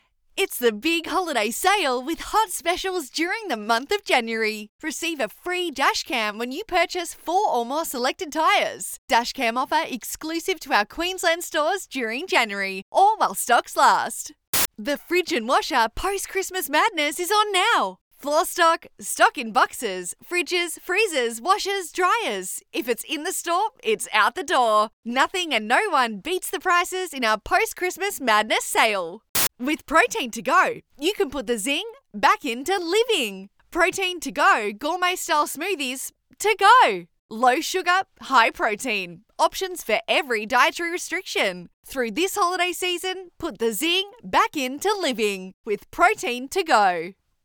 • Excited
• Young
• Natural